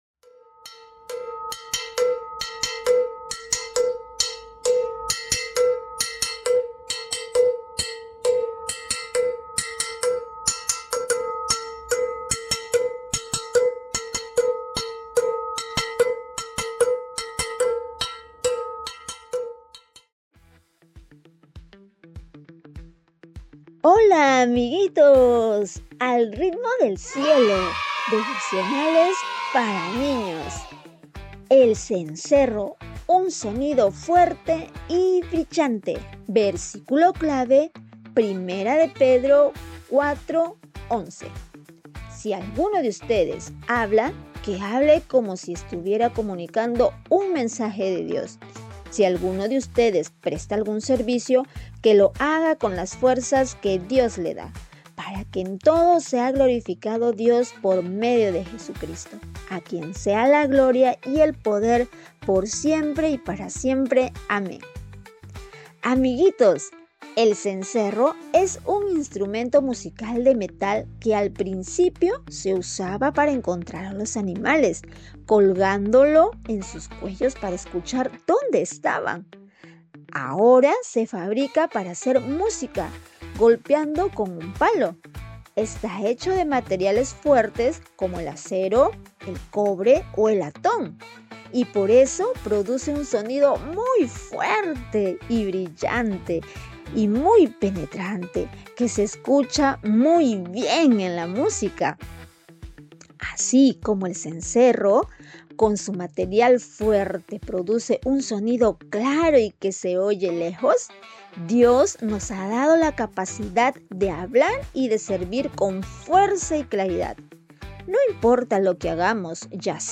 ¡Al Ritmo del Cielo! – Devocionales para Niños